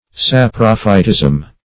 Saprophytism \Sap"ro*phyt*ism\, n. State or fact of being saprophytic.